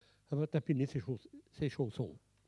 Enquête Arexcpo en Vendée
Catégorie Locution